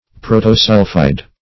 Search Result for " protosulphide" : The Collaborative International Dictionary of English v.0.48: Protosulphide \Pro`to*sul"phide\, n. [Proto- + sulphide.] (Chem.) That one of a series of sulphides of any element which has the lowest proportion of sulphur; a sulphide with but one atom of sulphur in the molecule.